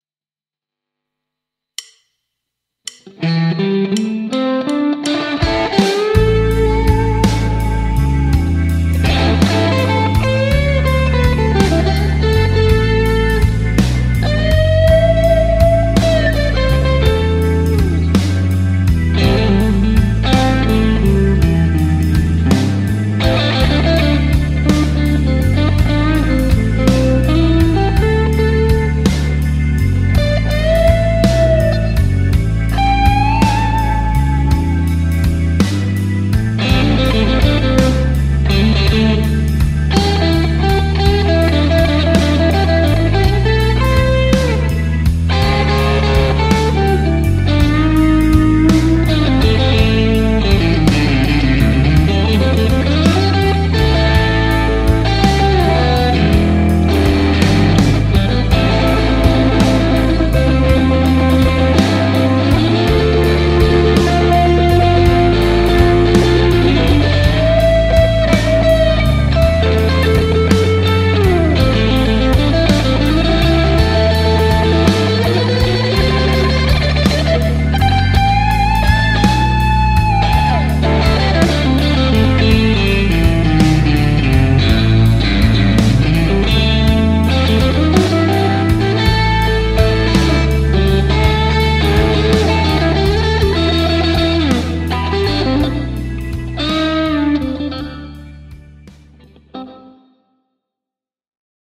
- soita soolosi annetun taustan päälle
- taustan tulee olla sointukierroltaan blues (I-IV-V)- sointuja ja tyyliä soveltaen.
hyvä dynamiikka ja soundi, hieno tunnelman vaihto toiselle kiepille.
nyt on fiilistä ja klubimeinkii
Kiva soundi ja valittava veto, kuten bluesissa kuuluukin.